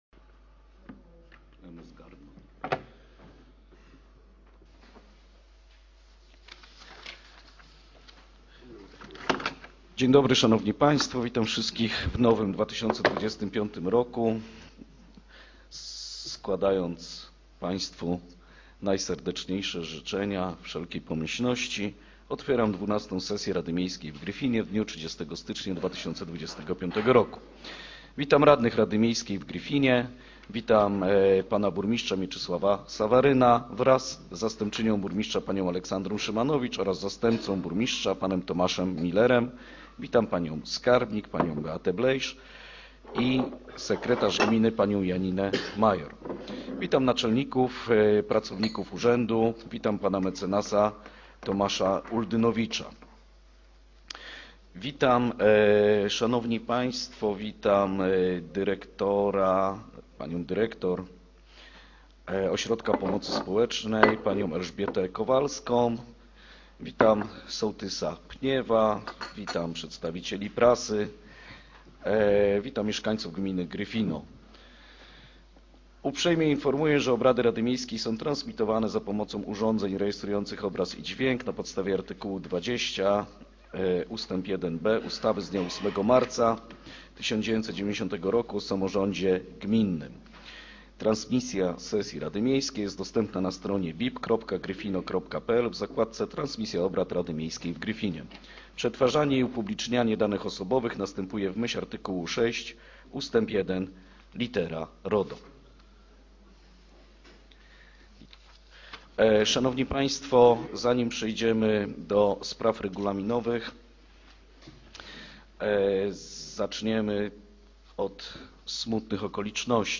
Zapis audio przebiegu XII SESJI RADY MIEJSKIEJ